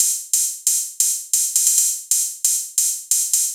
Ride Hat Loop 135bpm.wav